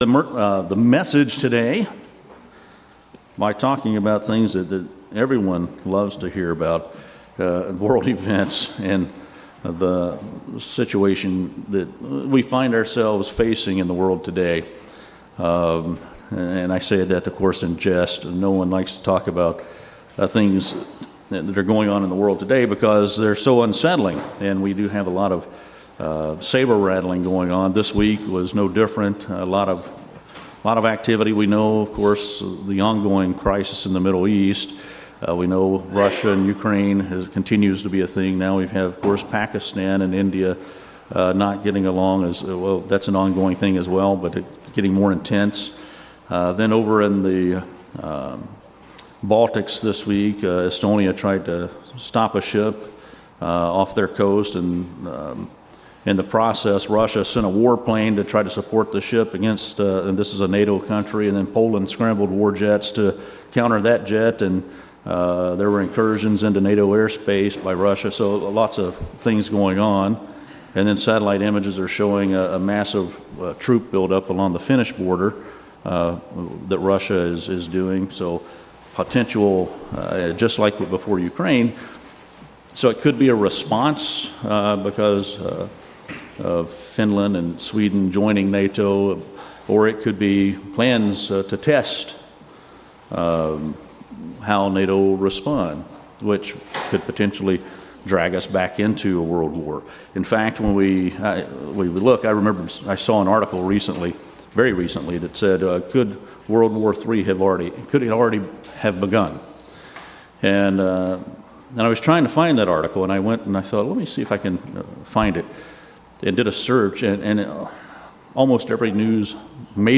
In this sermon, we look at the state of the world and the defensive posture many nations are taking as they watch what is going on, prepare for conflict, and stand ready with allies. As Christians we must do the same as we prepare for what's to come in the future.
Given in Jacksonville, FL